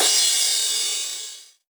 VTS1 Nightfly Kit 138BPM Crash DRY.wav